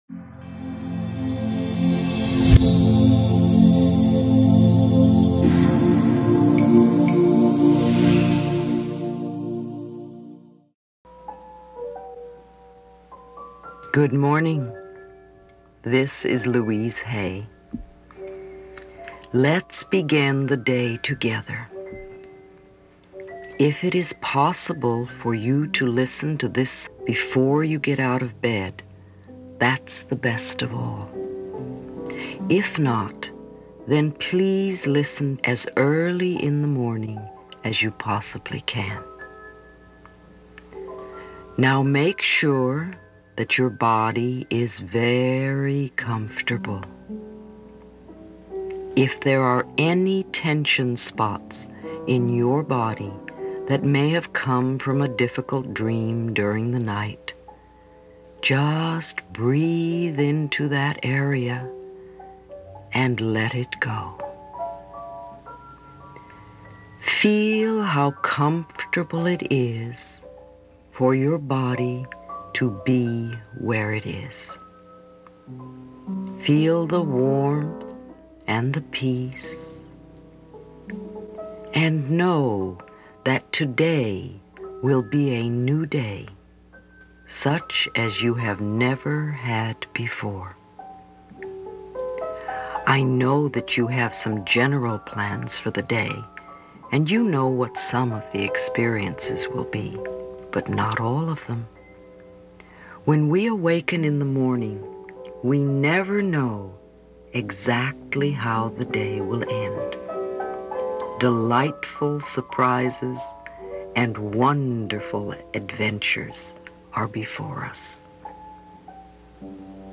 24 minutes, before getting up
Louise Hay’s Morning Meditation.mp3